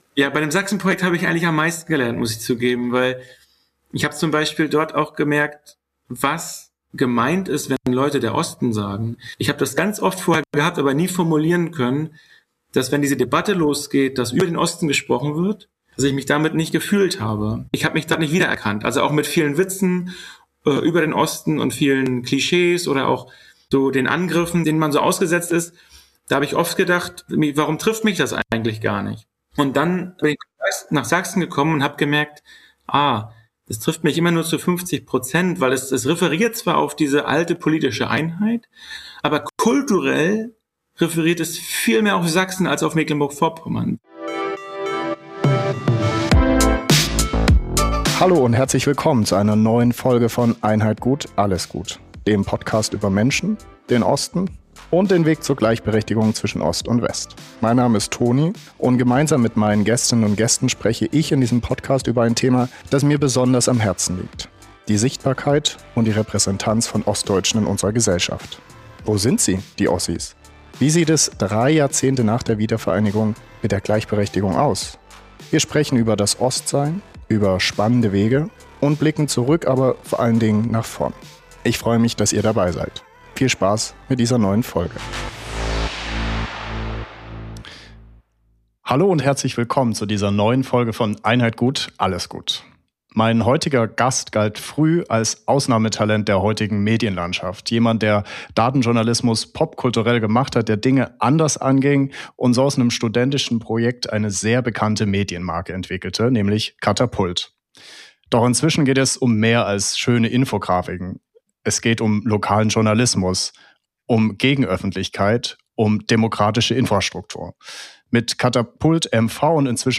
Ein Gespräch über Verantwortung, Risiko und darüber, ob Lokaljournalismus tatsächlich ein Hebel für demokratische Stabilität sein kann.